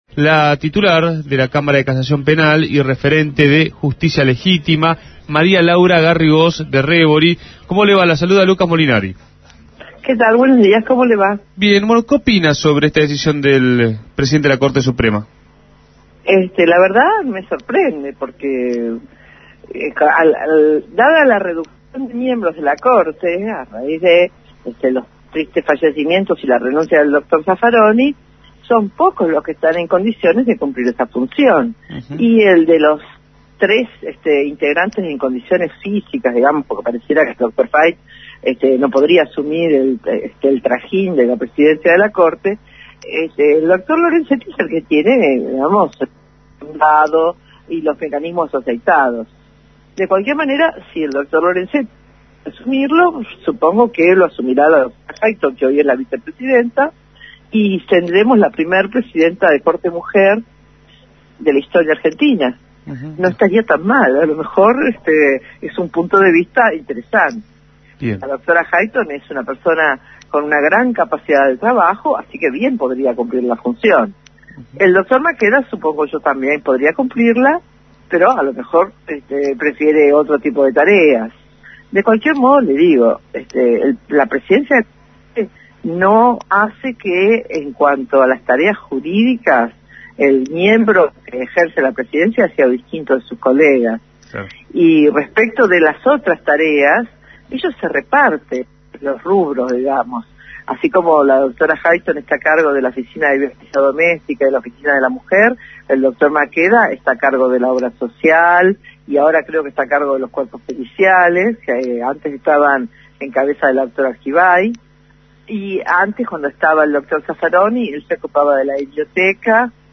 María Laura Garrigós de Rébori, titular de la Cámara de Casación Penal y referente de la agrupación Justicia Legítima, analizó este planteo de Lorenzetti en comunicación con Punto de Partida.